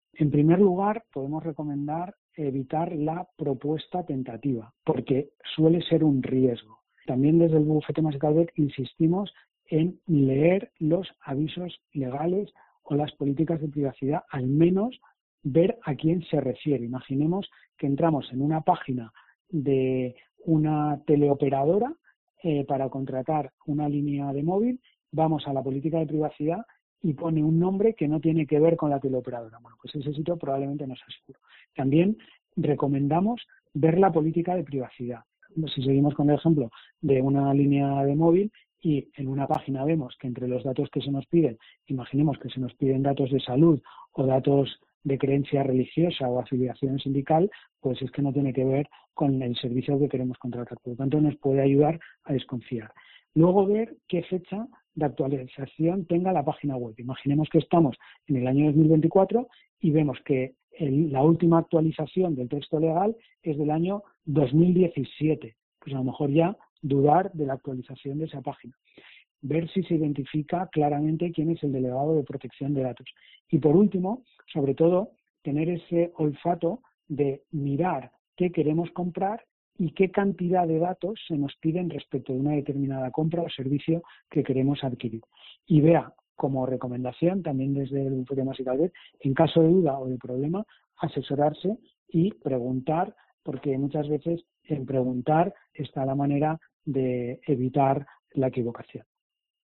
Un abogado señala en Poniendo las Calles recomendaciones a la hora de desvelar cuestiones personales.